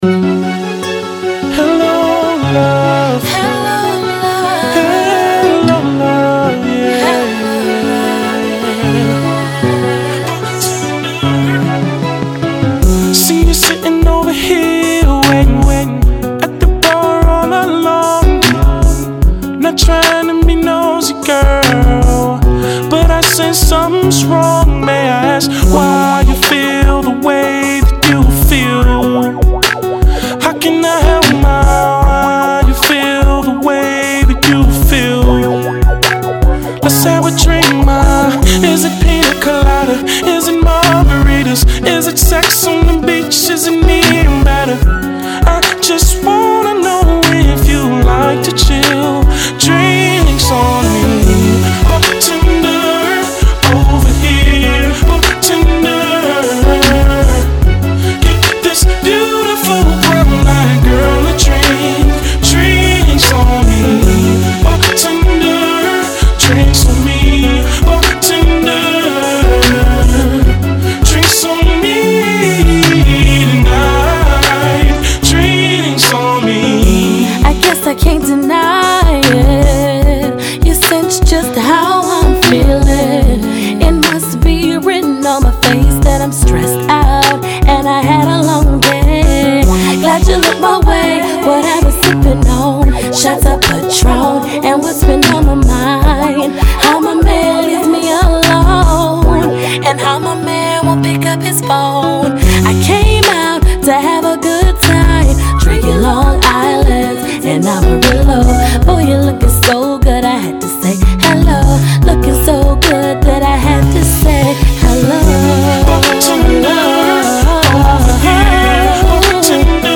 Smooth R&B